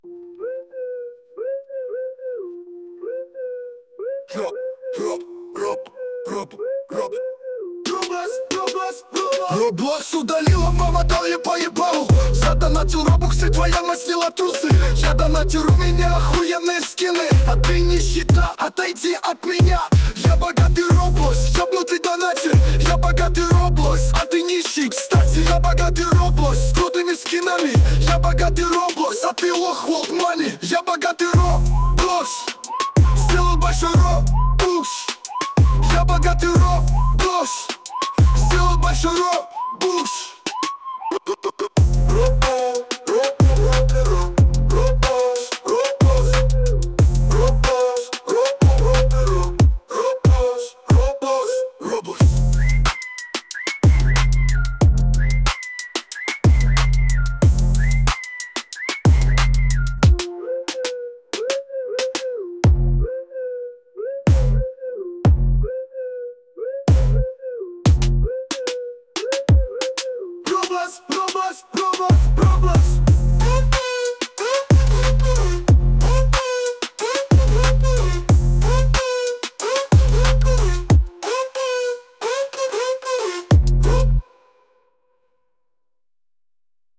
хип-хоп/рэп